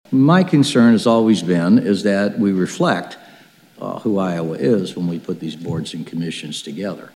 State Senator Tony Bisignano, a Democrat from Des Moines, says he’s concerned by the recommendation to get rid of the so-called gender balance requirement that men and women be represented equally on state boards and commissions.